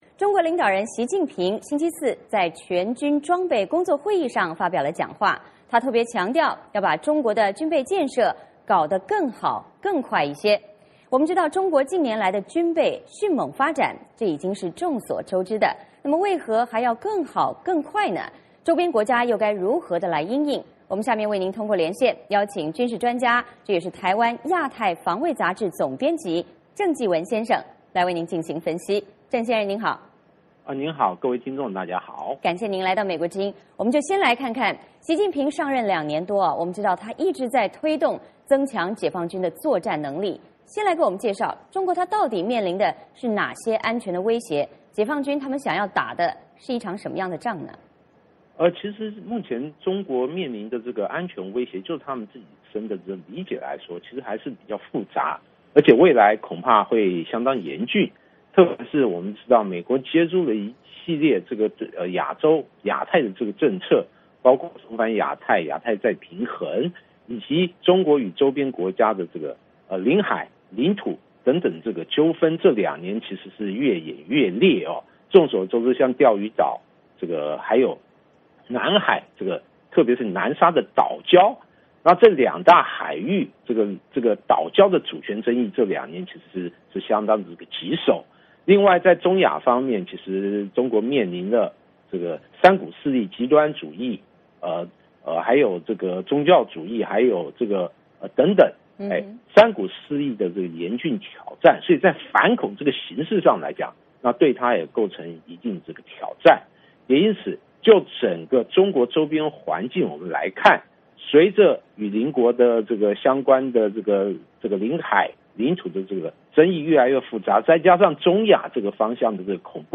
VOA连线：习近平加快军备建设，为因应哪些挑战？